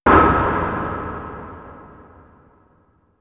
Shocked.wav